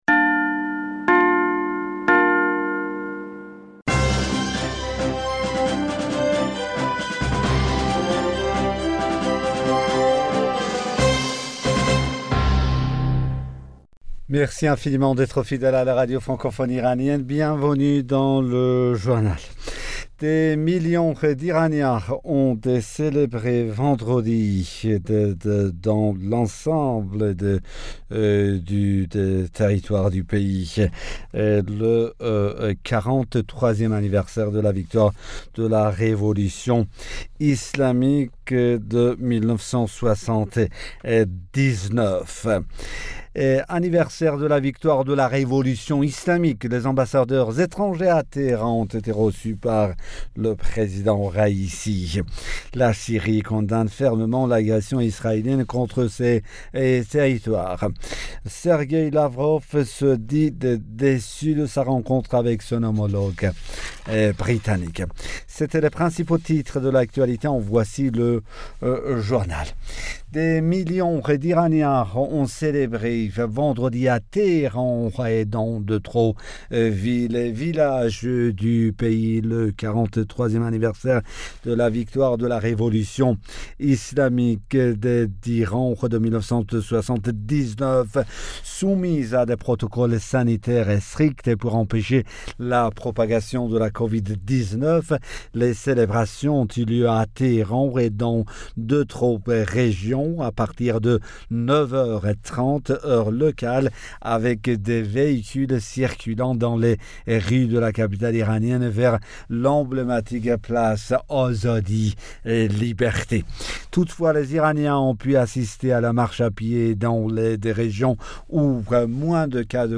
Bulletin d'information Du 11 Fevrier 2022